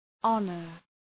Shkrimi fonetik {‘ɒnər}
honor.mp3